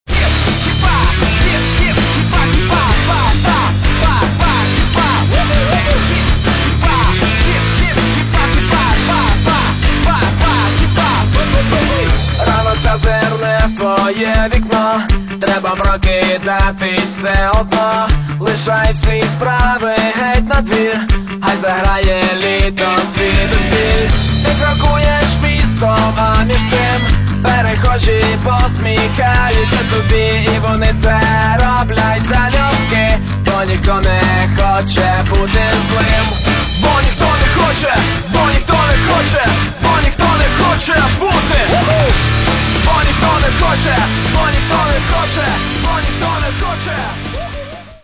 Каталог -> Хіп-хоп